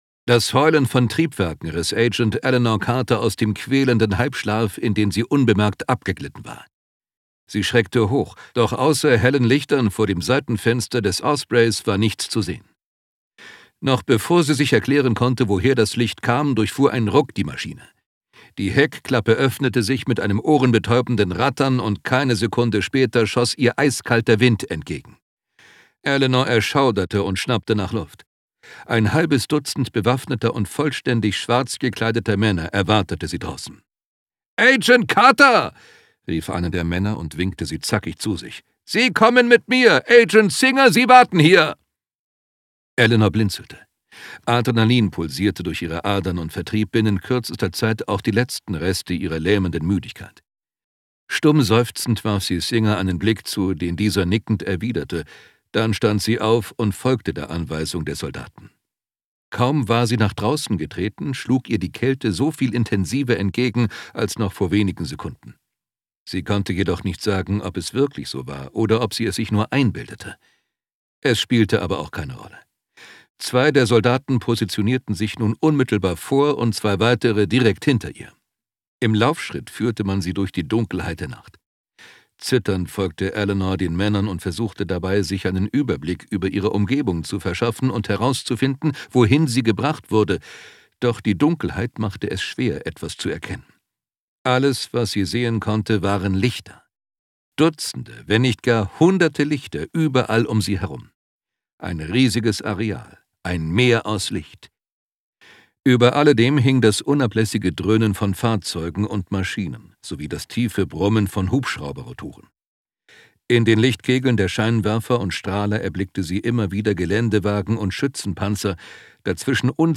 Ungekürzte Lesung